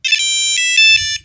Короткий звон